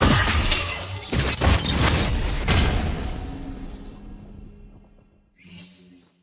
sfx-tier-wings-promotion-to-diamond.ogg